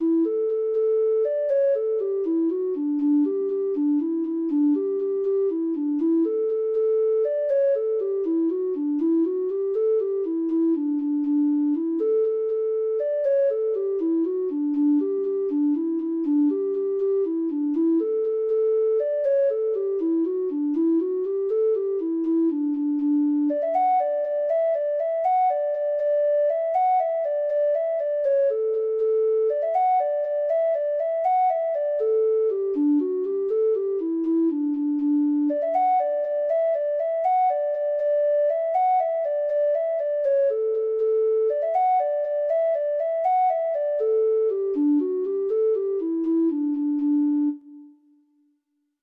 Free Sheet music for Treble Clef Instrument
Irish